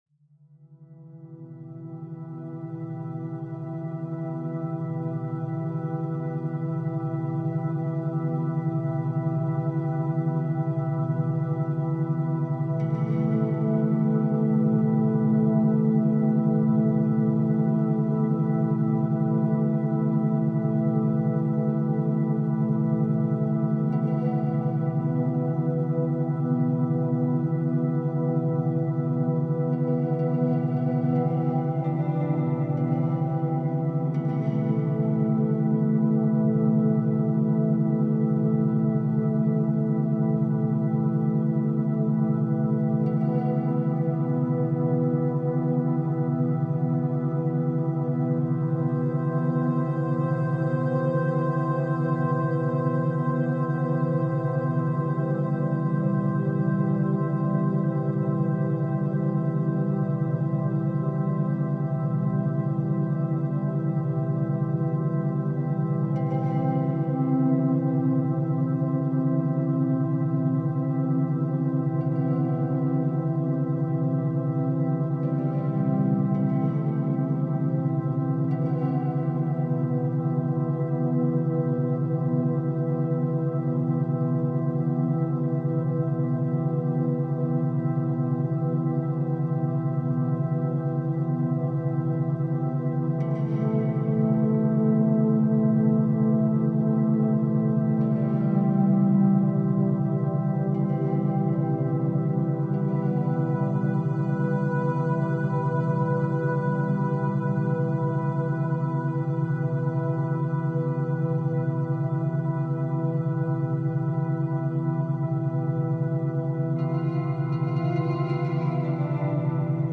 Sleep Induction – Fall Asleep Fast with Delta Wave Meditation